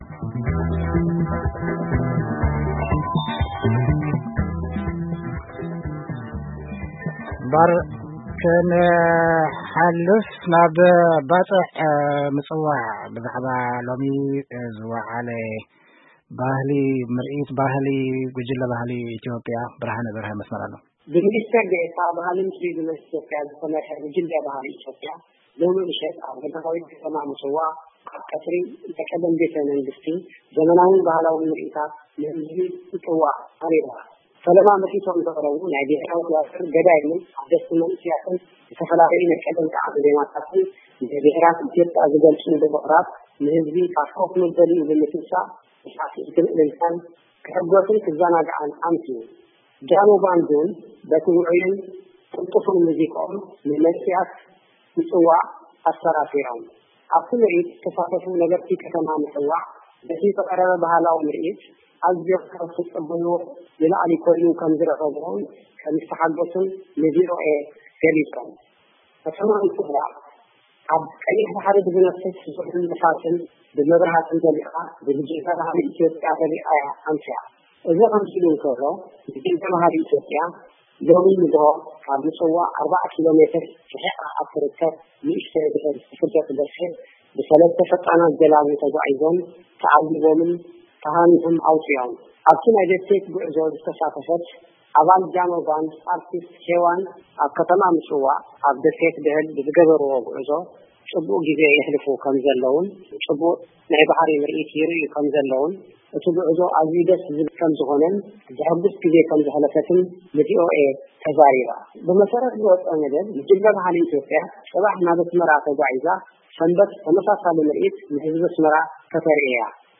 ጸብጻብ
ካብ ምጽዋዕ